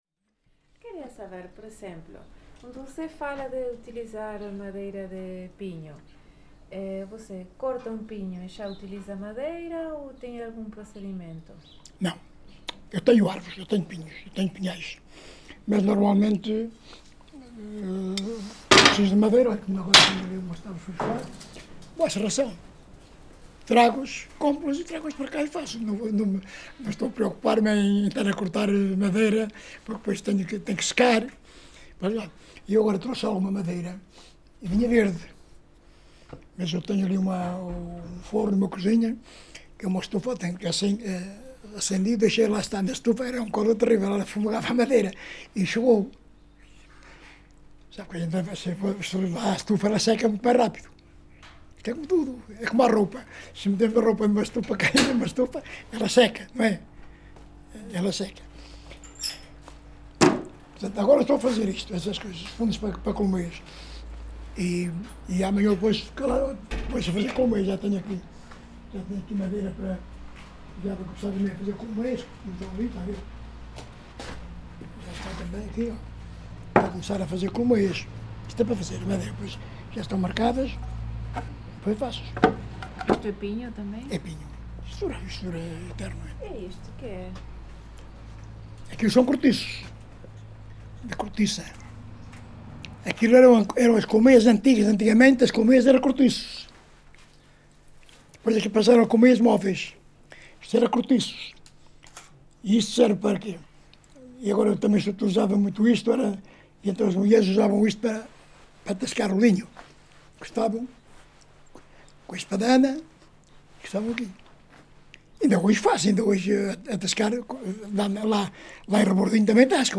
Várzea de Calde, primavera de 2019.
Tipo de Prática: Inquérito Oral